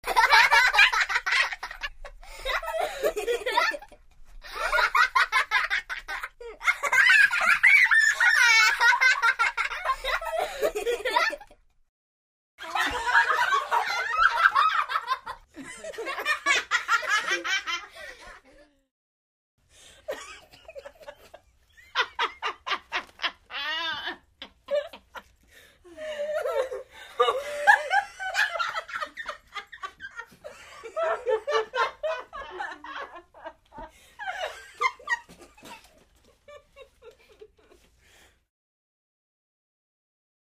Детский смех